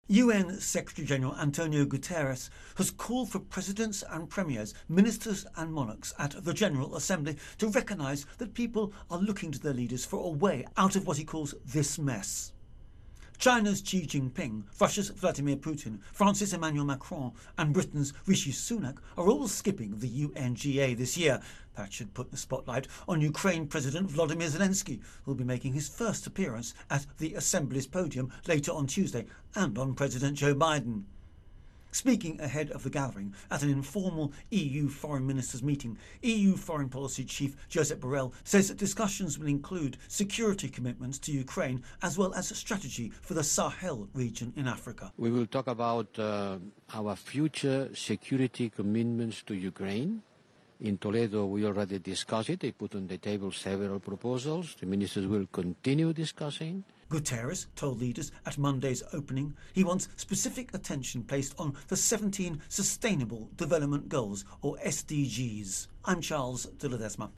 reports on UN General Assembly Day One-Borrell intro wrap